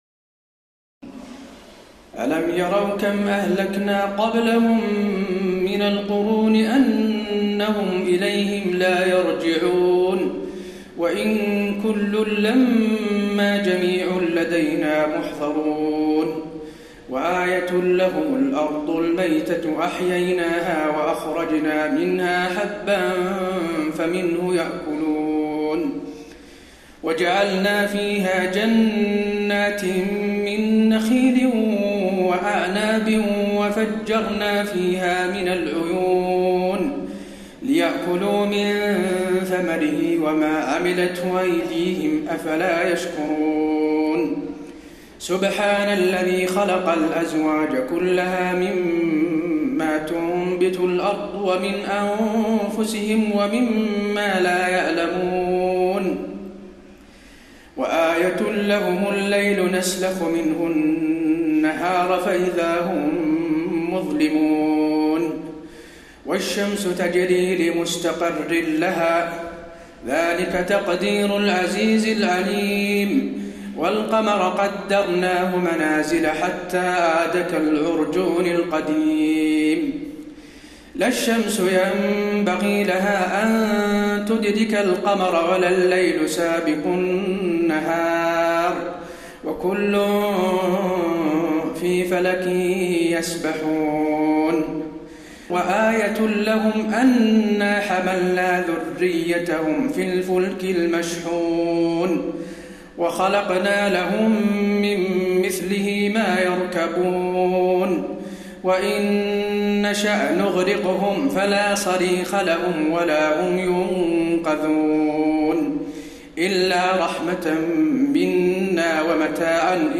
تراويح ليلة 22 رمضان 1432هـ من سور يس (31-83) و الصافات(1-138) Taraweeh 22 st night Ramadan 1432H from Surah Yaseen and As-Saaffaat > تراويح الحرم النبوي عام 1432 🕌 > التراويح - تلاوات الحرمين